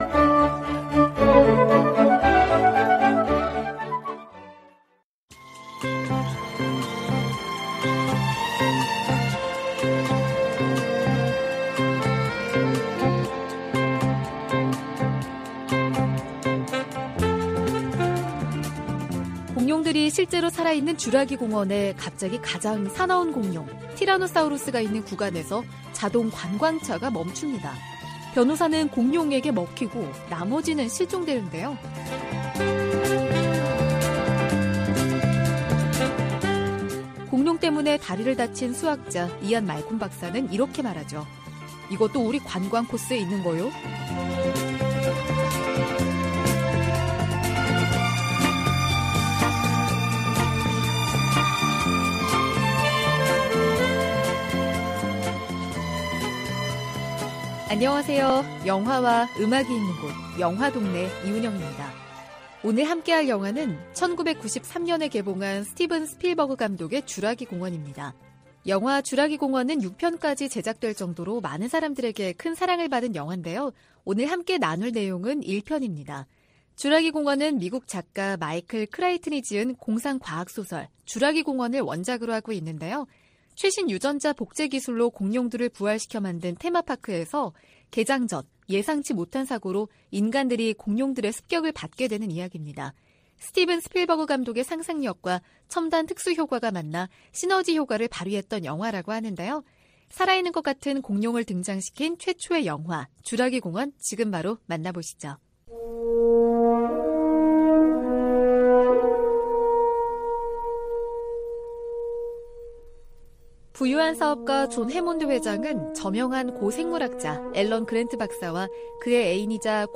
VOA 한국어 방송의 일요일 오전 프로그램 2부입니다.